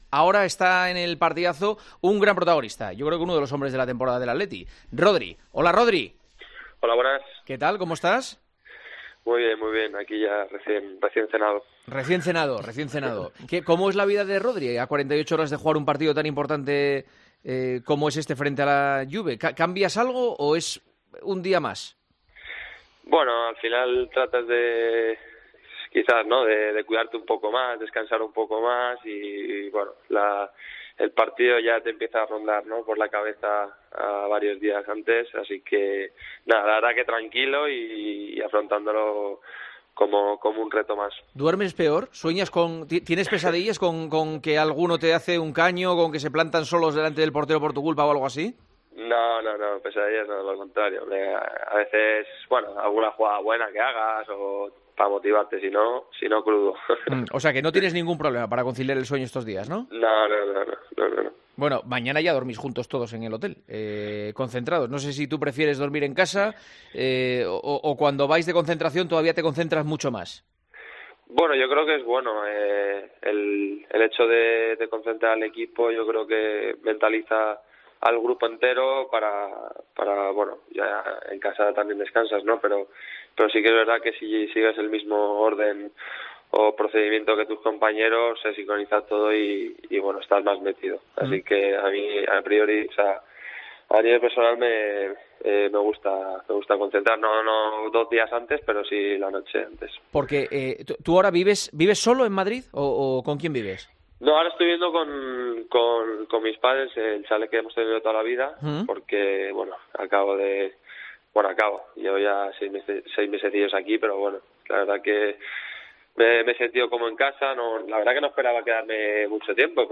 dijo en la entrevista concedida este lunes a El Partidazo de COPE , con Juanma Castaño .